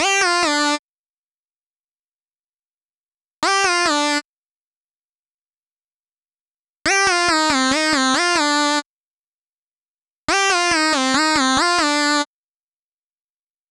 VTS1 Space Of Time Kit 140BPM Main Lead DRY.wav